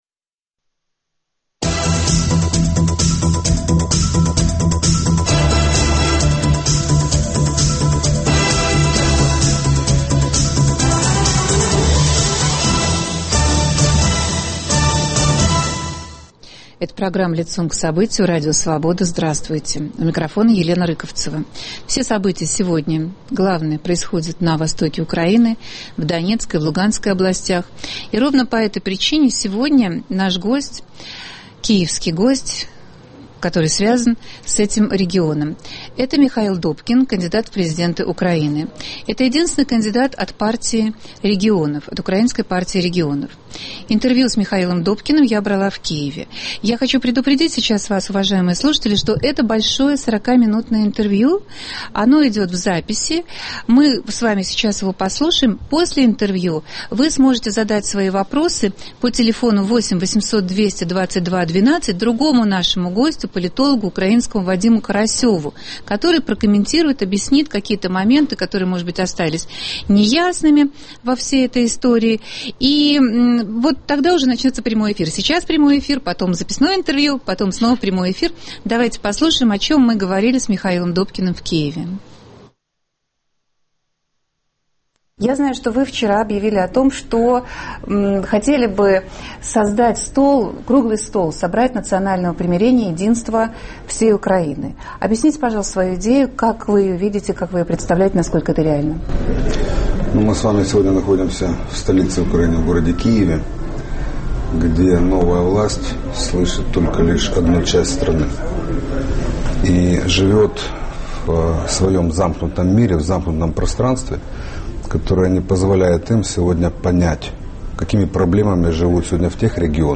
Кандидат в президенты Украины Михаил Добкин знает, как успокоить восток страны и вернуть Крым. Об этих своих идеях он рассказал корреспонденту Радио Свобода.